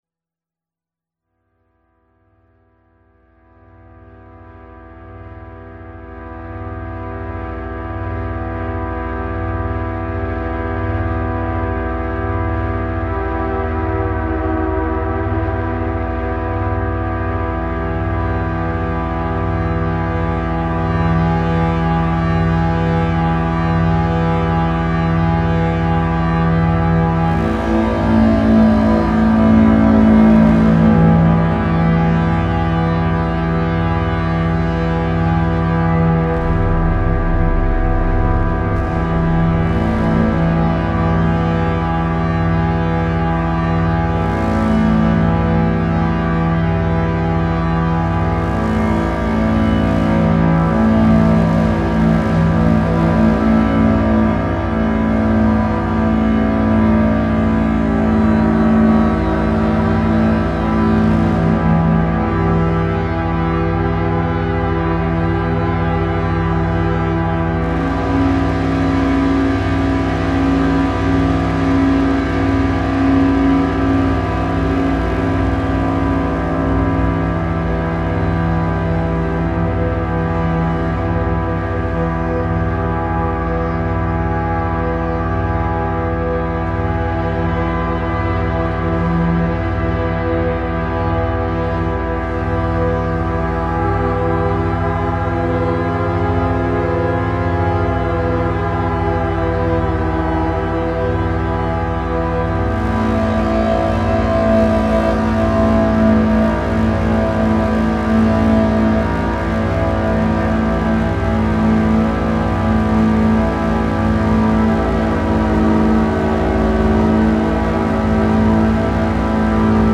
Going through the Tascam and deleting old files and came across this.
I really like the cello/bowed cymbal sounds happening.
its on the chill side. I found it quite pretty